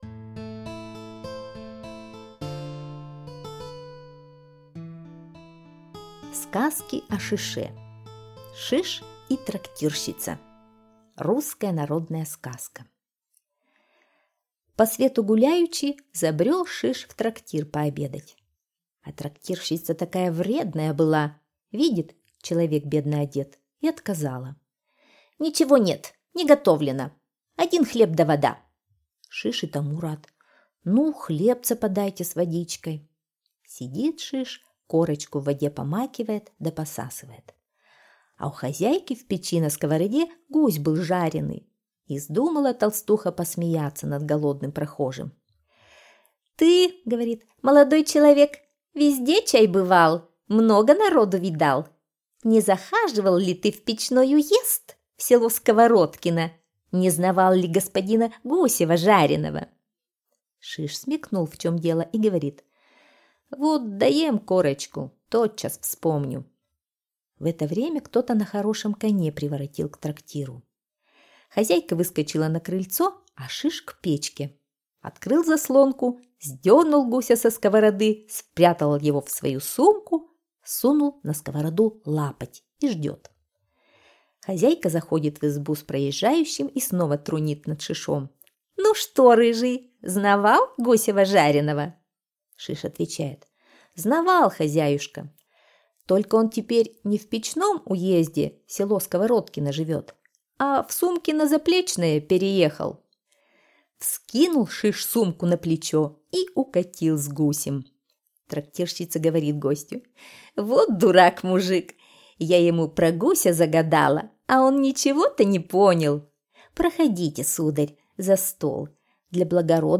Сказки о Шише - русская народная аудиосказка. Сказка про смекалистого парня Шиша, который заехал в трактир пообедать.